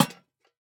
Minecraft Version Minecraft Version latest Latest Release | Latest Snapshot latest / assets / minecraft / sounds / block / lantern / place5.ogg Compare With Compare With Latest Release | Latest Snapshot